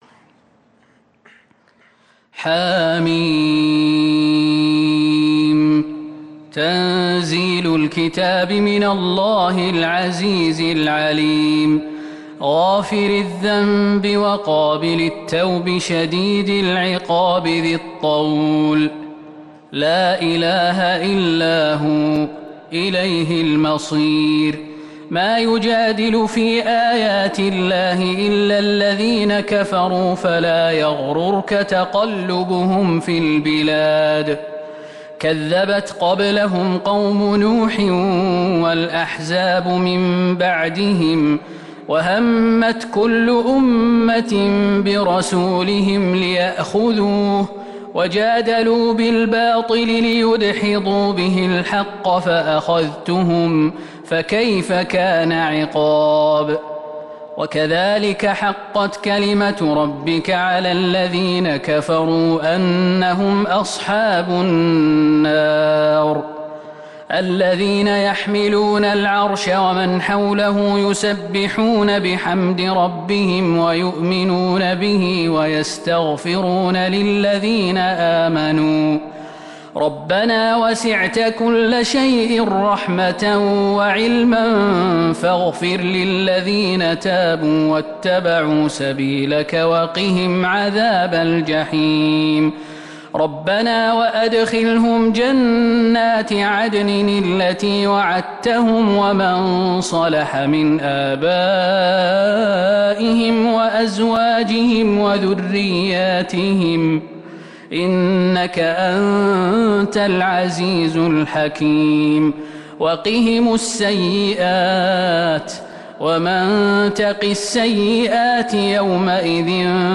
سورة غافر Surat Ghafir من تراويح المسجد النبوي 1442هـ > مصحف تراويح الحرم النبوي عام 1442هـ > المصحف - تلاوات الحرمين